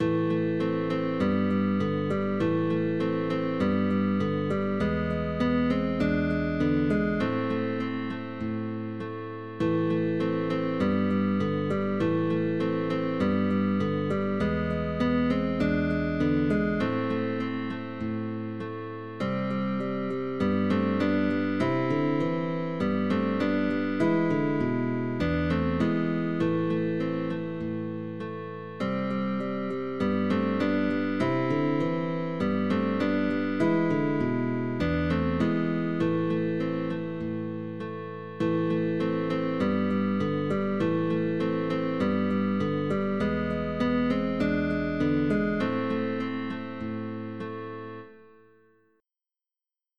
Original sheetmusic by guitar trio.